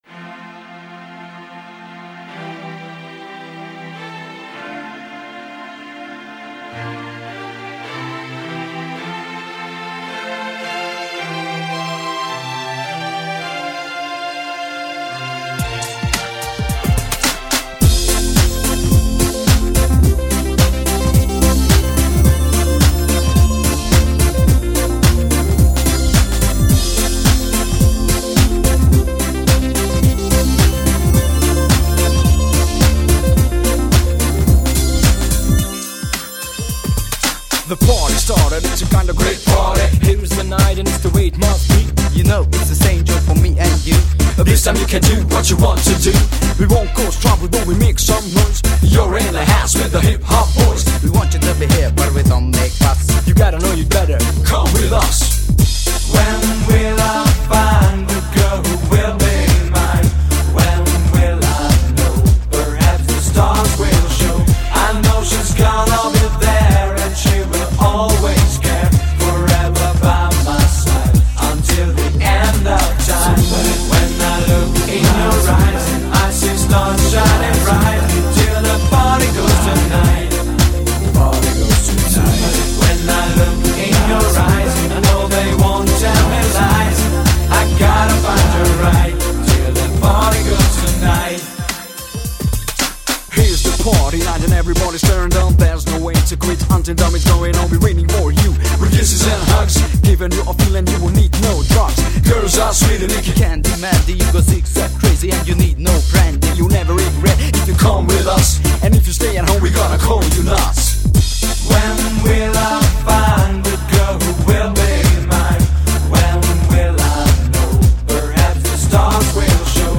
Неплохая тоже песенка, с энергетическим посылом